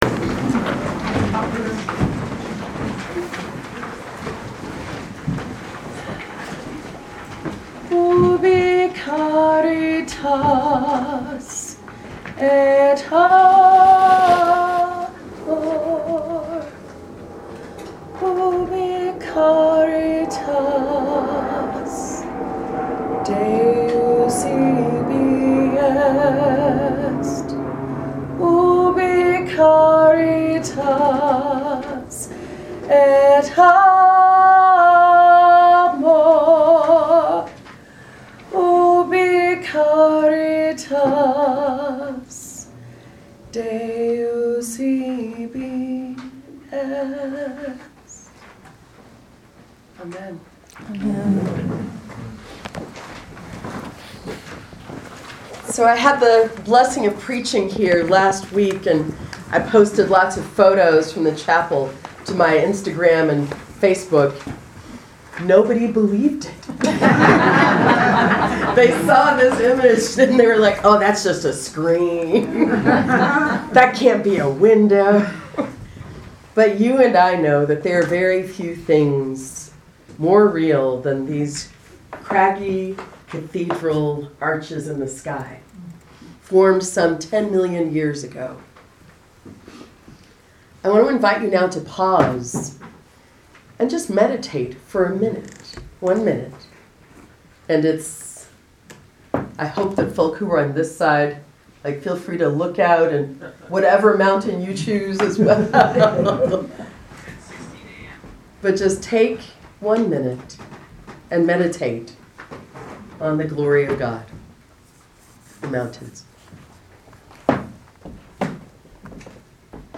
Read the transcript of the sermon. Joshua 24:1-2a,14-18 Psalm 34:15-22 Ephesians 6:10-20 John 6:56-69